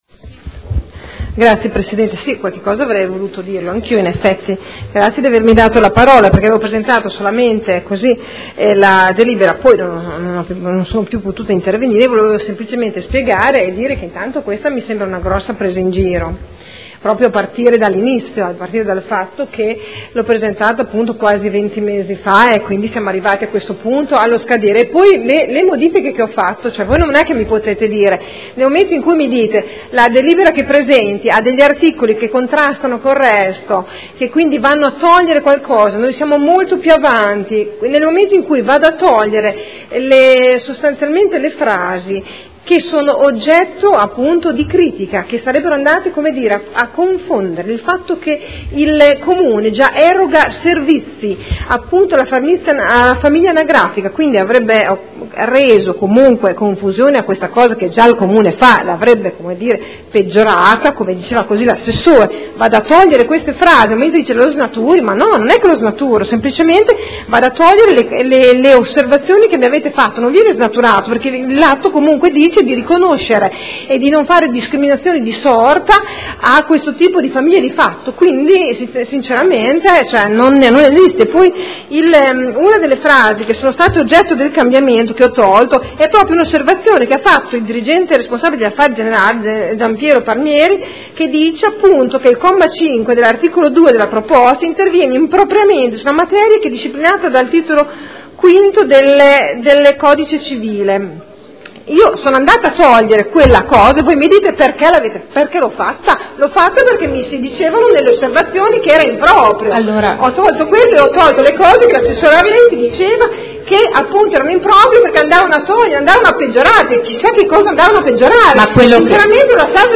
Seduta del 3 aprile. Comunicazione della consigliera Poppi sulla delibera con oggetto le unioni civili